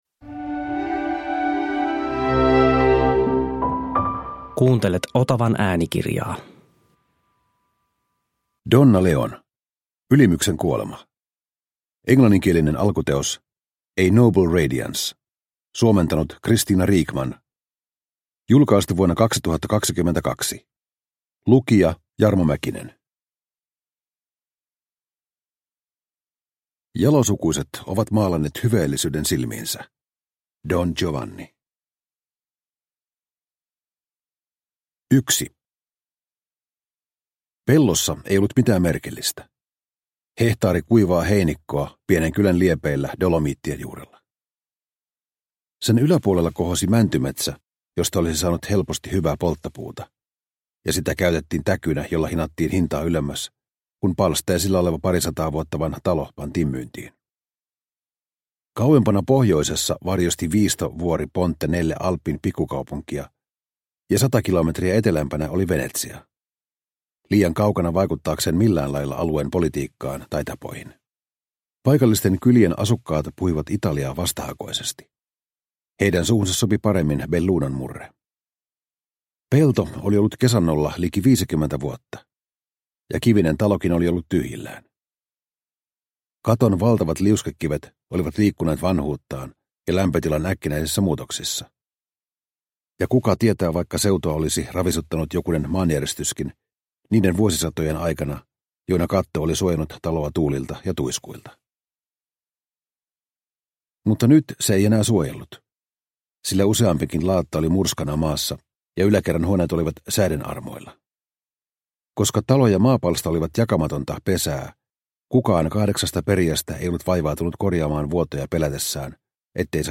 Ylimyksen kuolema – Ljudbok – Laddas ner